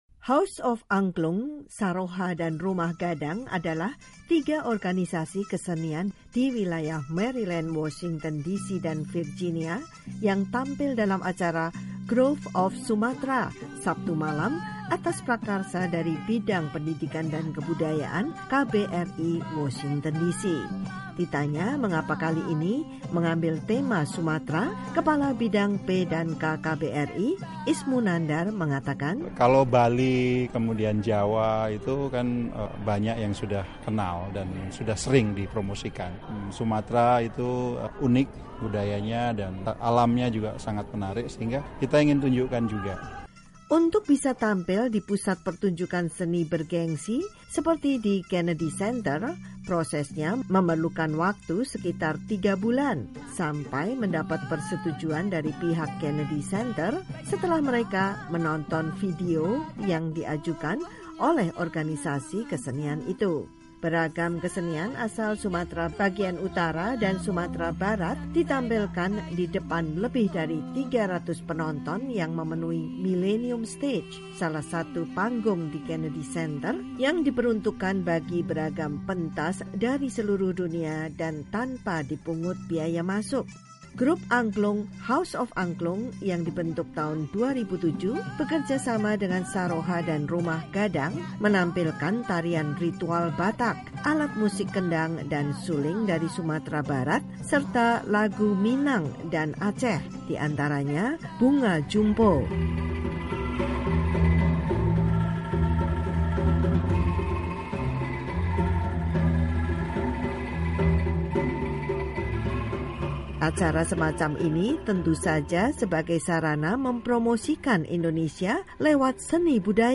Kelompok kesenian Rumah Gadang memainkan musik gendang dan suling.
Tiga kelompok kesenian Sumatra di Washington, DC tampil di Pusat Pertunjukan Seni bergengsi, Kennedy Center, dalam acara "Groove of Sumatra".
Grup Angklung, House of Angklung yang dibentuk tahun 2007 bekerja sama dengan Saroha dan Rumah Gadang, menampilkan tarian ritual Batak, alat musik kendang dan suling dari Sumatra Barat serta lagu Minang dan Aceh, di antaranya Bunga Jumpo.
Penonton diberi kesempatan mencoba bermain angklung.